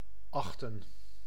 Ääntäminen
IPA: ['ɑxtə(n)]